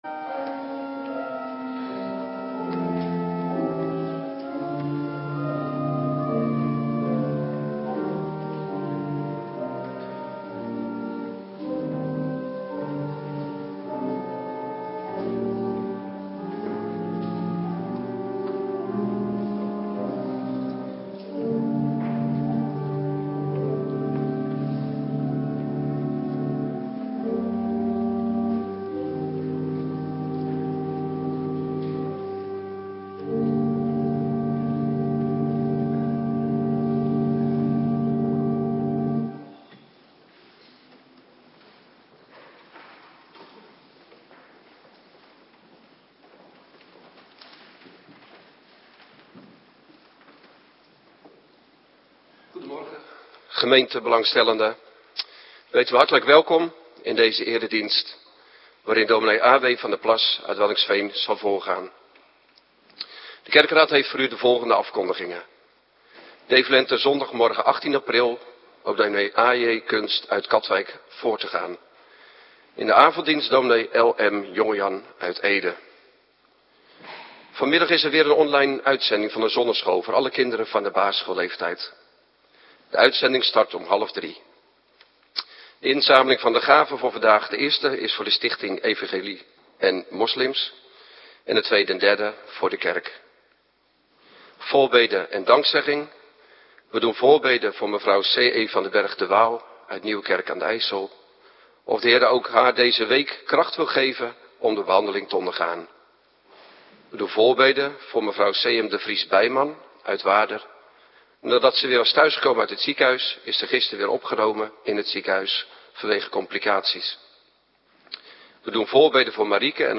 Morgendienst - Cluster 1
Locatie: Hervormde Gemeente Waarder